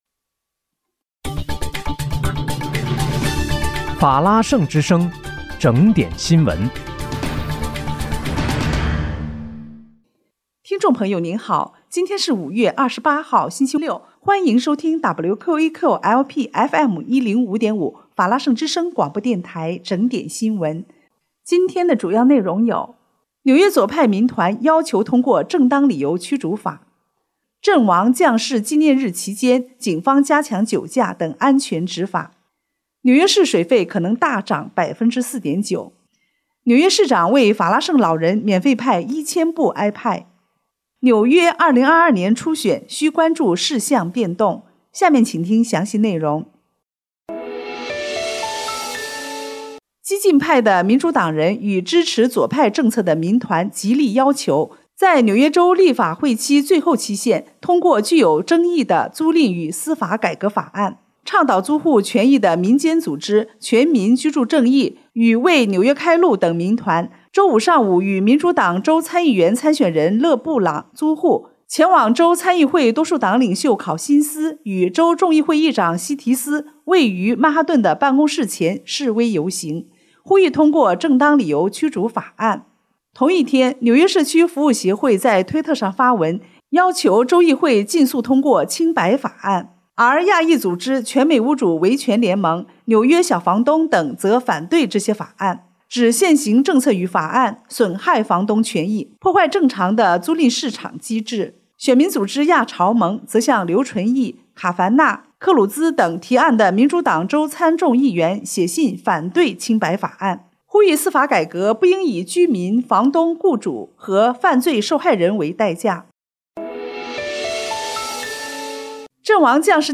5月28日（星期六）纽约整点新闻